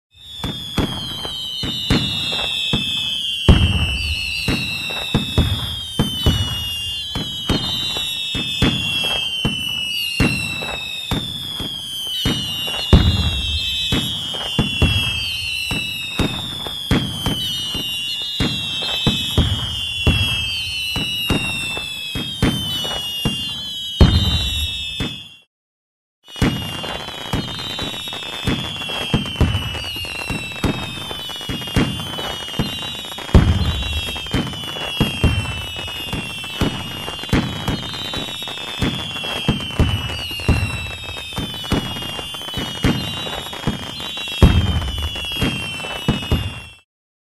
Звуки фейерверка, салюта
На этой странице собраны разнообразные звуки фейерверков и салютов: от одиночных хлопков до продолжительных залпов.
Вспышки фейерверка